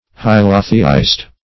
Hylotheist \Hy"lo*the*ist\, n. One who believes in hylotheism.
hylotheist.mp3